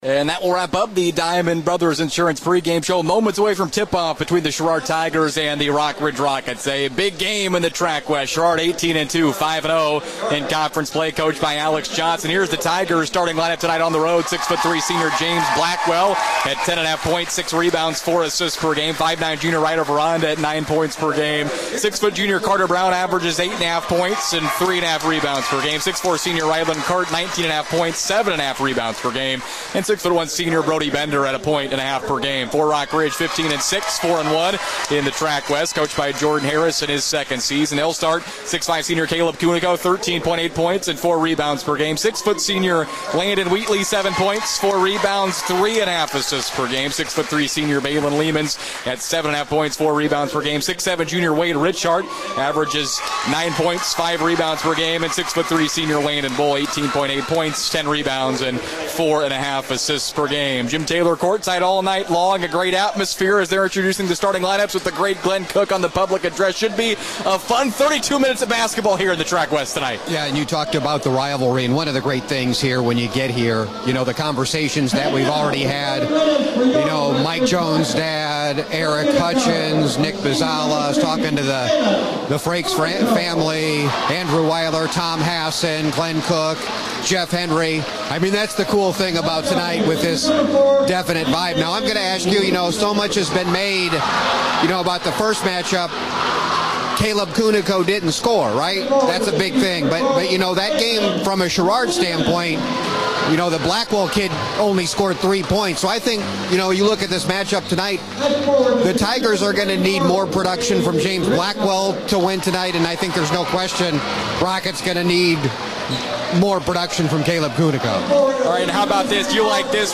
WRMJ Sports - FULL GAME: Sherrard @ Rockridge - Boys Basketball, Jan. 24, 2025